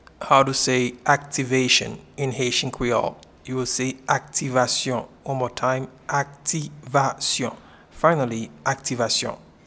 Pronunciation and Transcript:
Activation-in-Haitian-Creole-Aktivasyon.mp3